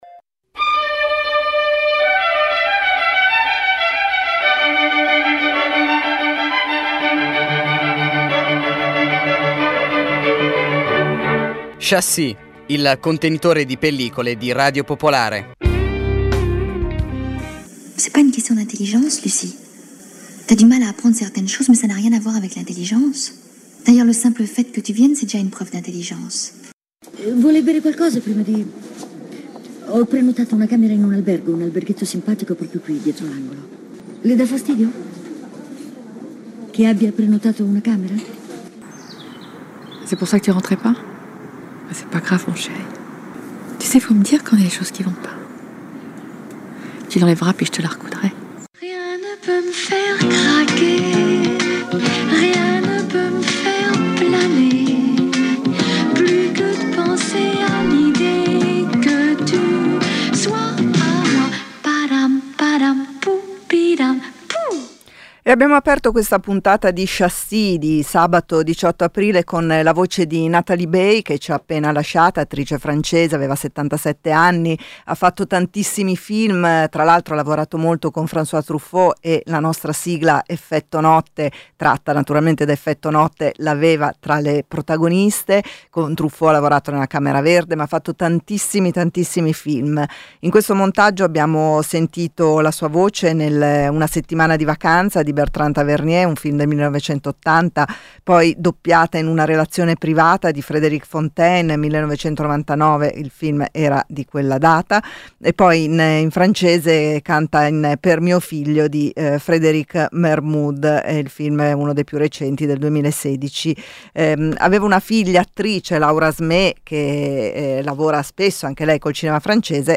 Ogni sabato offre un'ora di interviste con registi, attori, autori, e critici, alternando parole e musica per evocare emozioni e riflessioni cinematografiche. Include notizie sulle uscite settimanali, cronache dai festival e novità editoriali. La puntata si conclude con una canzone tratta da colonne sonore.